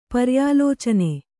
♪ paryālōcane